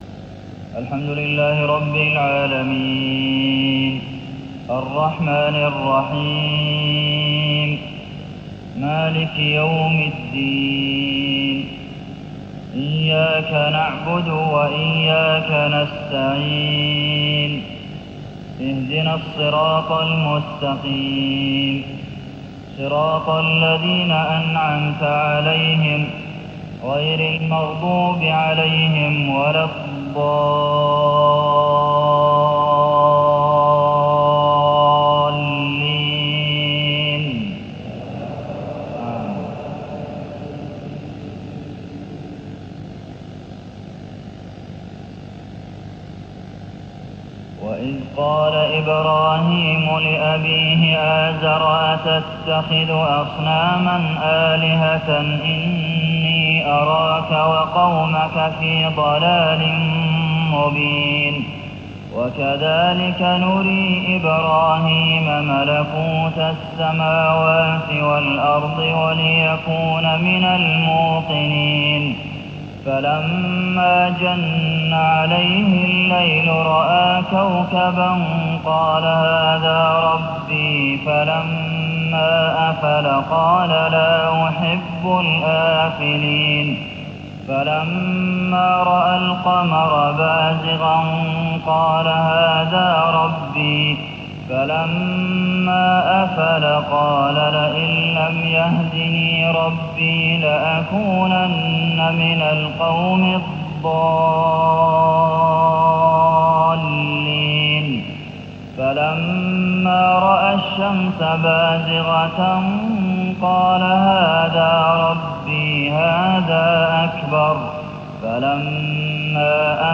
صلاة الفجر رمضان 1420هـ من سورة الأنعام 74-90 > 1420 🕌 > الفروض - تلاوات الحرمين